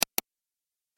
دانلود آهنگ کلیک 49 از افکت صوتی اشیاء
دانلود صدای کلیک 49 از ساعد نیوز با لینک مستقیم و کیفیت بالا
جلوه های صوتی